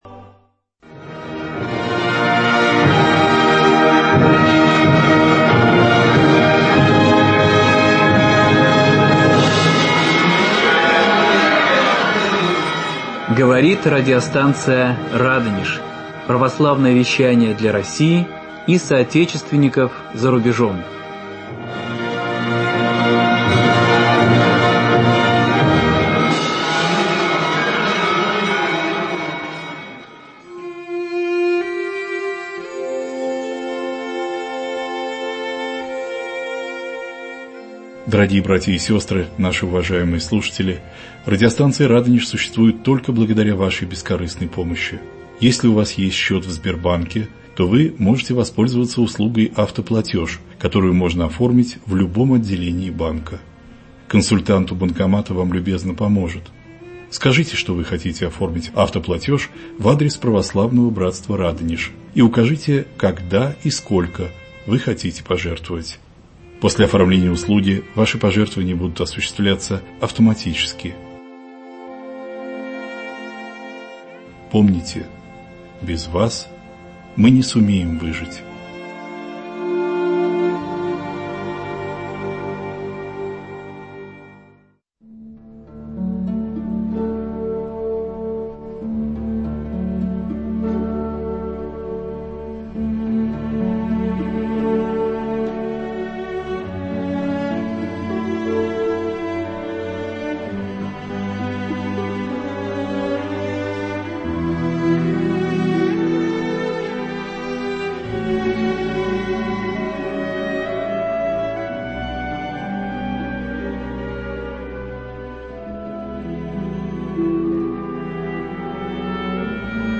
Прямой эфир.